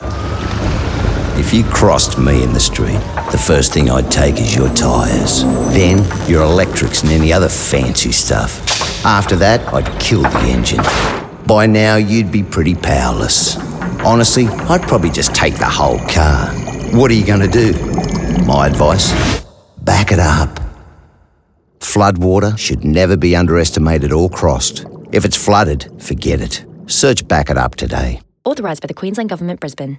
30s RADIO FINAL v01